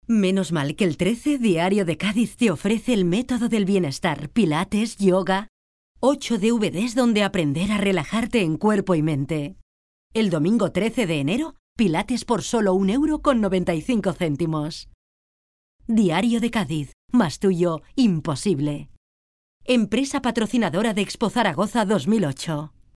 Female Spanish Voice Over. Dubbing.
Professional female voice over.